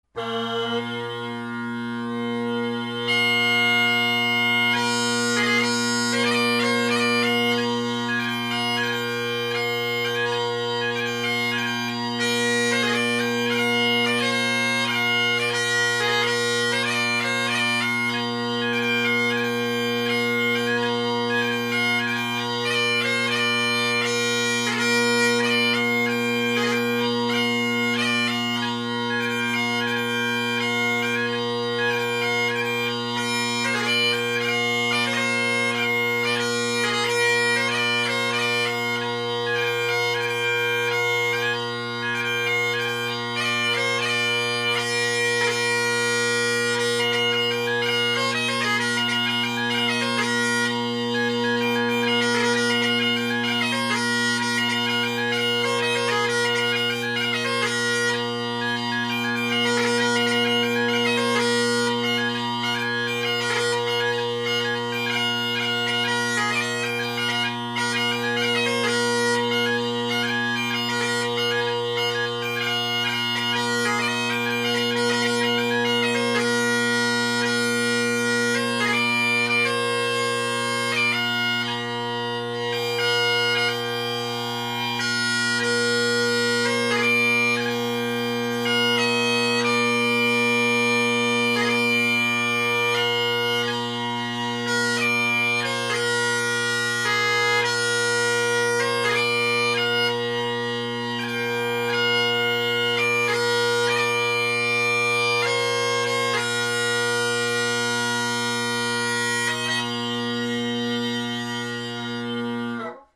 Drone Sounds of the GHB, Great Highland Bagpipe Solo
Here are recordings where the drones are facing the mic.
51st Highland Division, Scarce o’ Tatties, and Deer Forest – Canning with polycarbonate bass